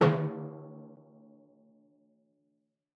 Timpani6D_hit_v5_rr1_main.mp3